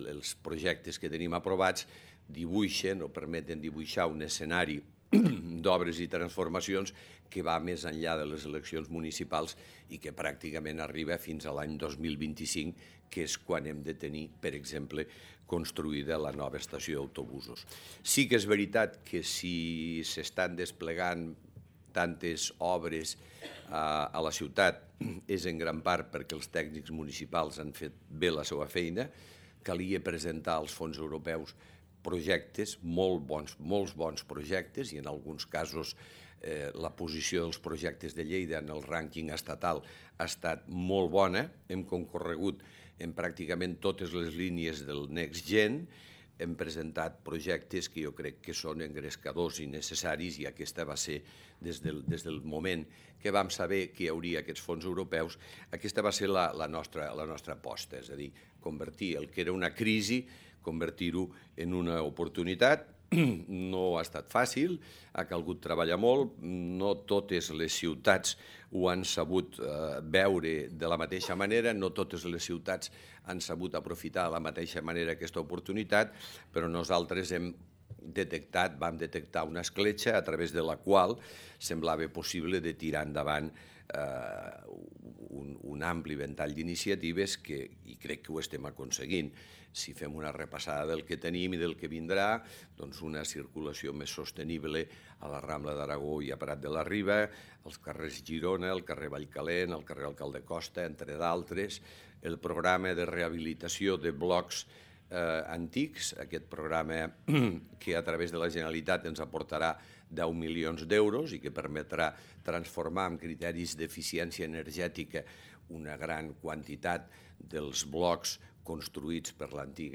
El paer en cap, Miquel Pueyo, ha ofert avui la tradicional conferència que organitza el Col·legi de Periodistes, on també ha remarcat la fita que suposa l’aprovació del pressupost per a l’any vinent, la pròxima finalització de les obres del Morera i les accions de renaturalització de la ciutat
tall-de-veu-del-paer-en-cap-miquel-pueyo-1